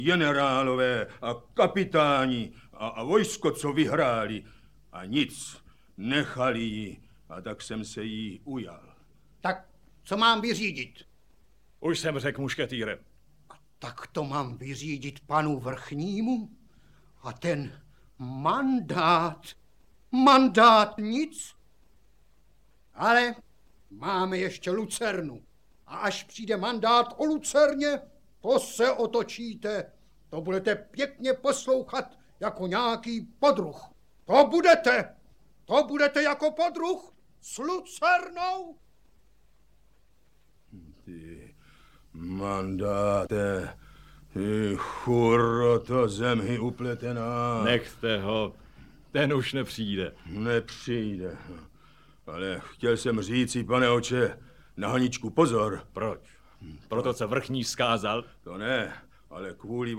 Audiobook
Read: František Filipovský